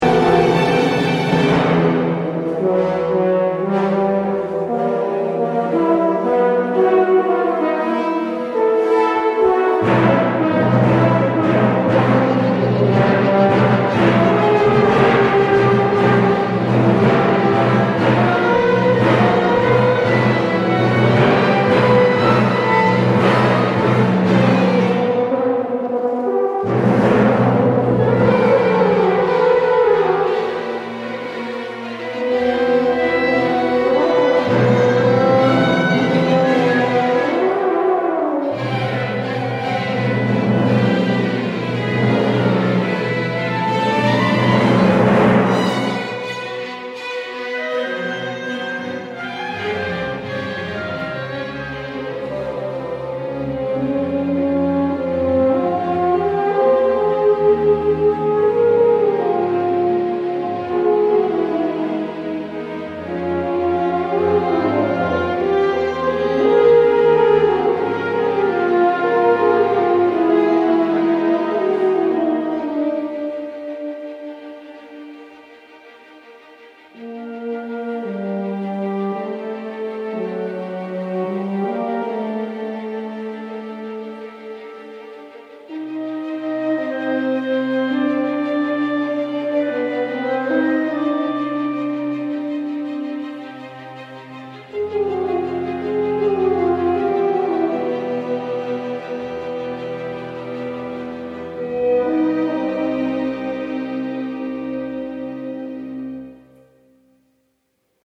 Concerto for Euphonium and Loopstation. Recorded live at Christ Church, Skipton on 8 November 2014